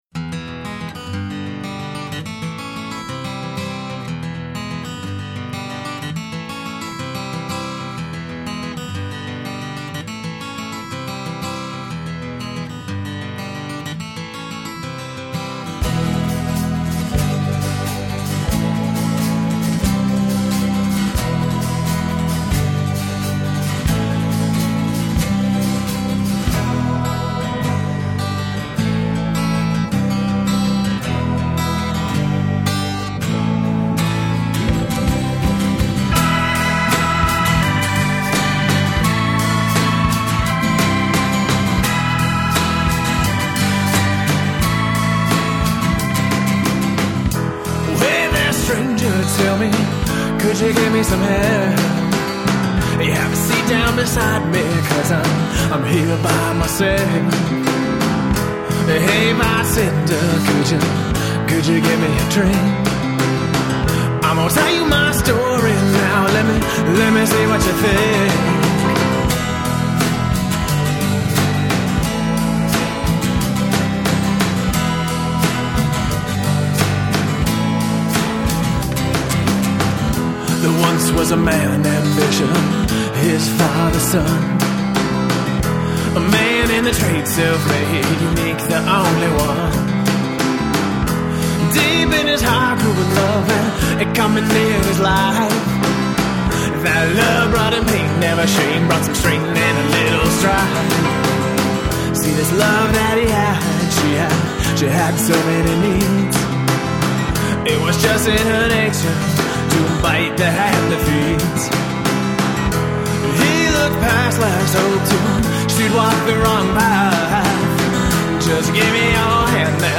Guitar, Vocals
Drums
Piano, Organ
Bass Guitar